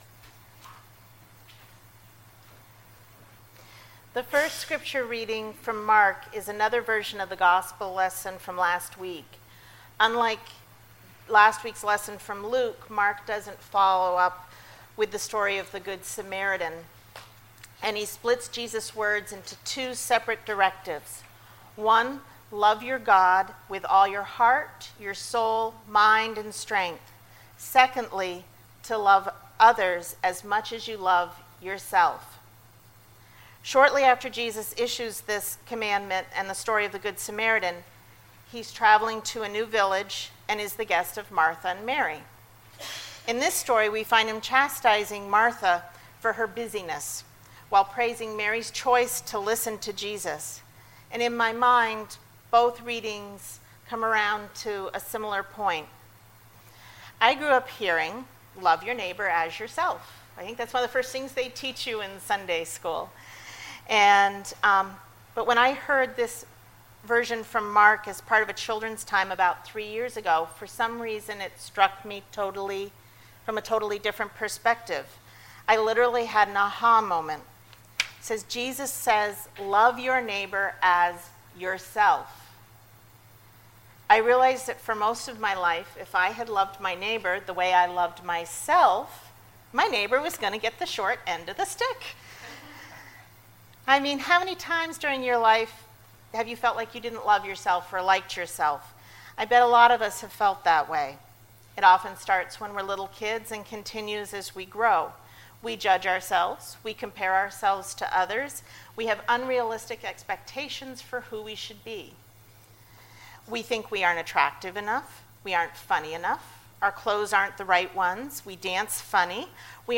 Sermons Were You Uncomfortable?